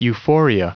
Prononciation du mot euphoria en anglais (fichier audio)
Prononciation du mot : euphoria